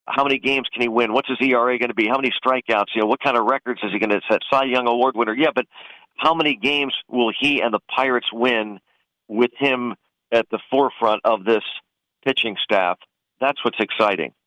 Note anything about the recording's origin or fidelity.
On Indiana In the Morning on WCCS yesterday